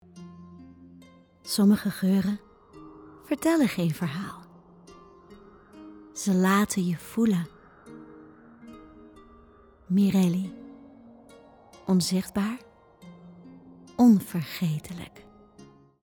Opvallend, Speels, Veelzijdig, Vriendelijk, Warm
Commercieel